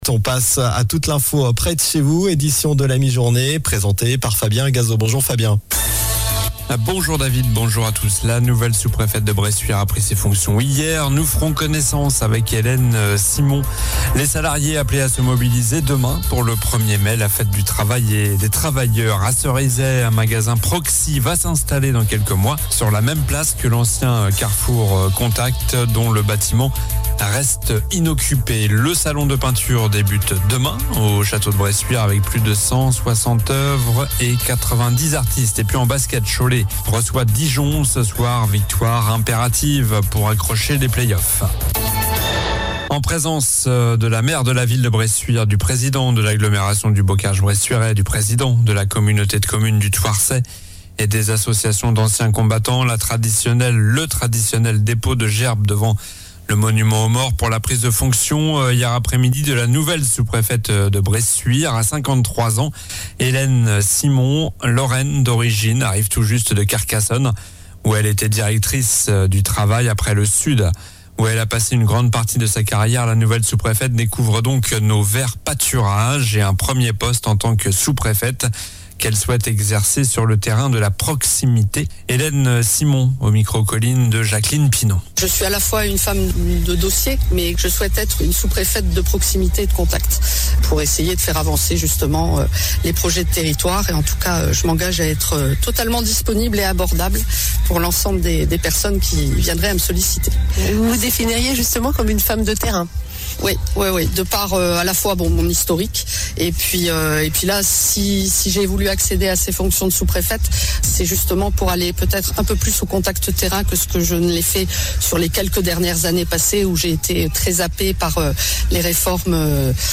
Journal du mardi 30 avril (midi)